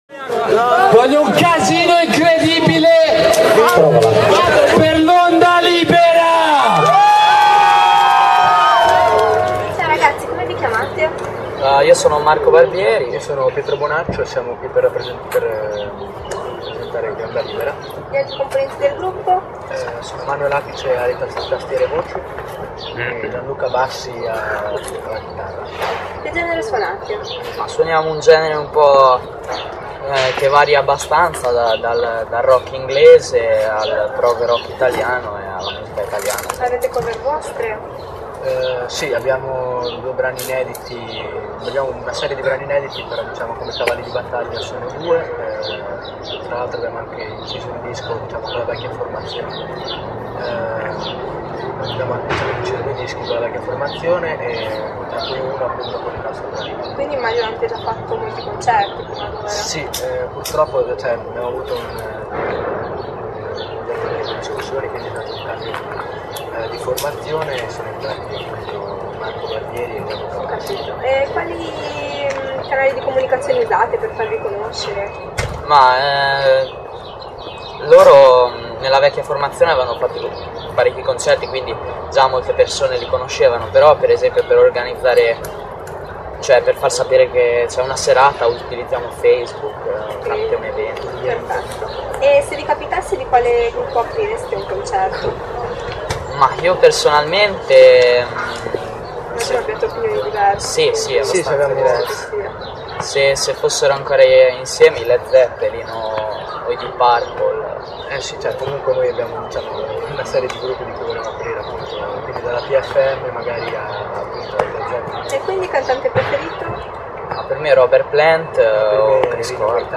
Intervista a Ondalibera